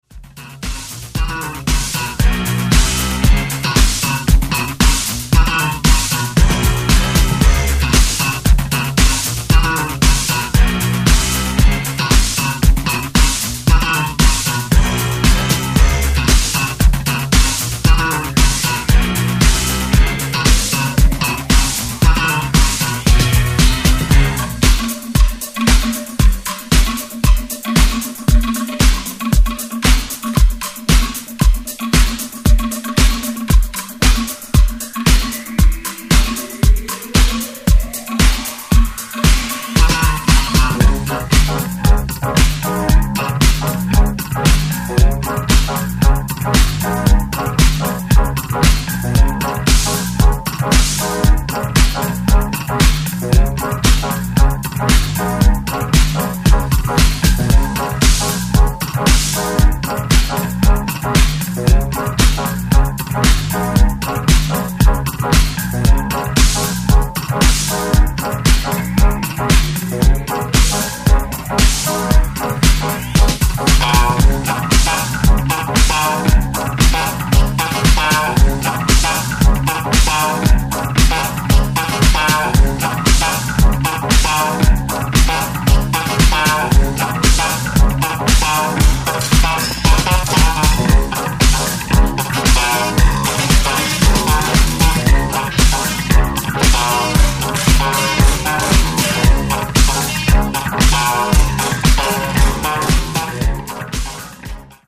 4 danceable boogie disco re-edits